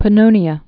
(pə-nōnē-ə)